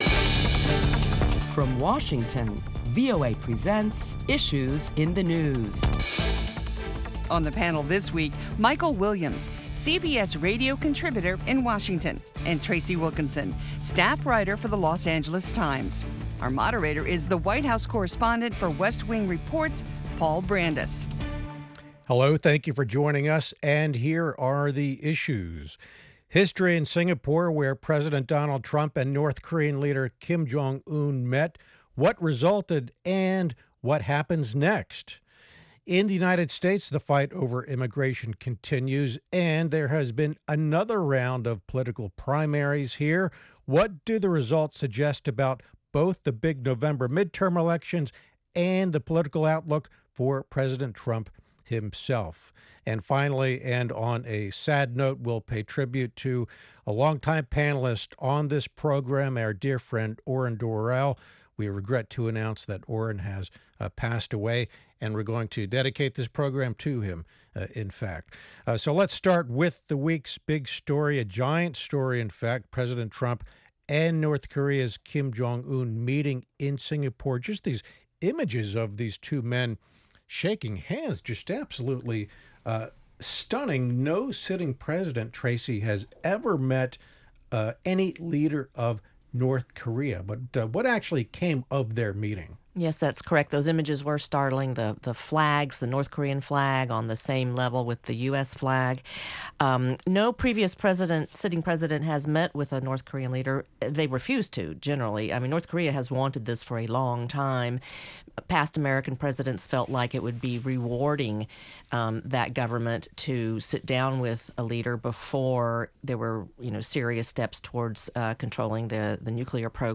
Join Washington correspondents for a lively discussion of the week's top stories including what they think President Trump accomplished in the summit with North Korean leader Kim Jong-un.